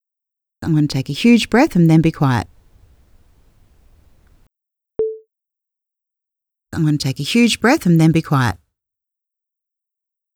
Recording woes - hum
If there are long intervals (>1second) where you are not speaking you could use a noise-gate to squelch the (very faint) hiss to true, flatline silence during those intervals.
Maybe this is just my hearing , but I’d Boost the treble : IMO the “th” of breath isn’t loud enough , boosting treble will make it louder.